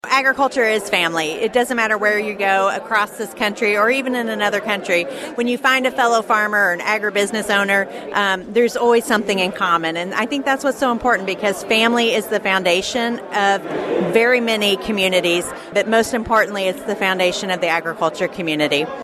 Chinn says events like KMMO Ag Day highlight the close ties that agriculture forges between farmers and their families.